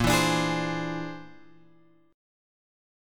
A# 9th Flat 5th